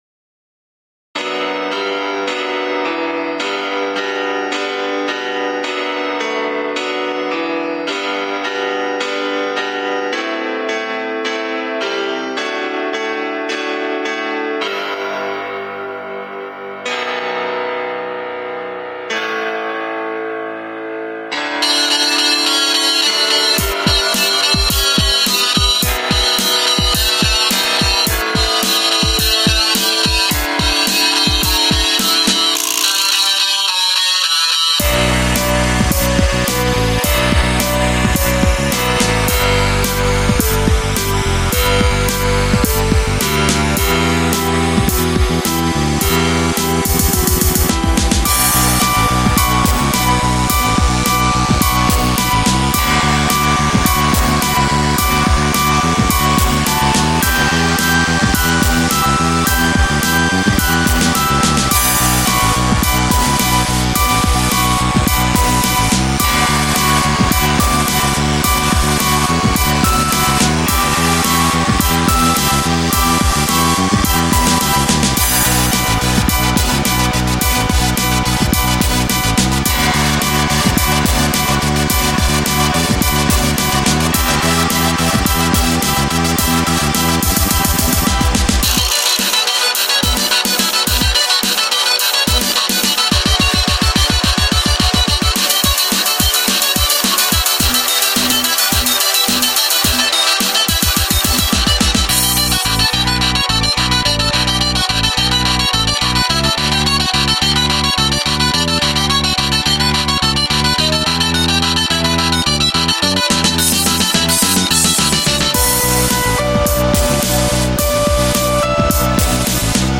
Žánr: Electro/Dance
NÁLADA ALBA JE PŘEVÁŽNĚ MELONCHOLICKÁ.